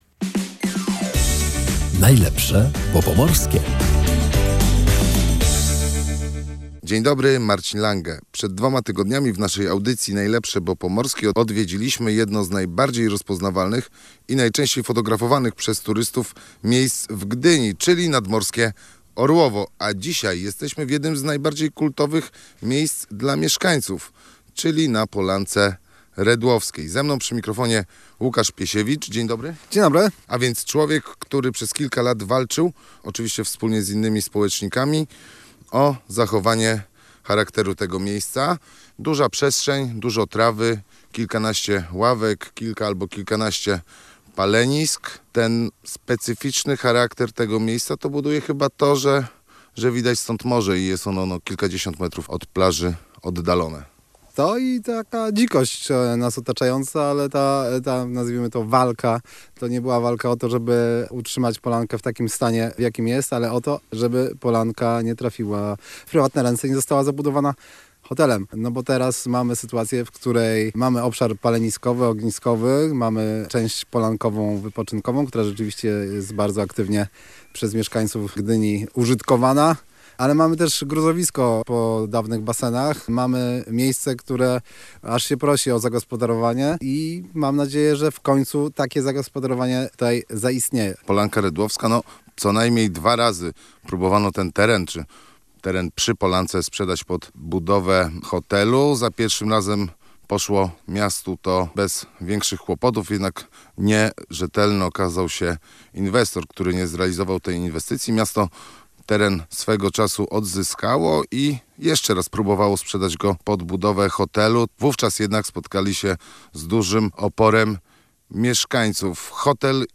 W audycji „Najlepsze, bo pomorskie” odwiedziliśmy jedno z najbardziej kultowych miejsc dla mieszkańców Gdyni – Polankę Redłowską.